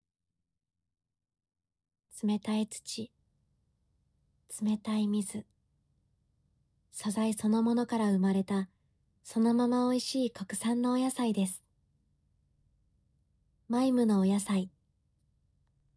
出⾝地・⽅⾔ 山口県・山口弁、関西弁
ボイスサンプル
CM２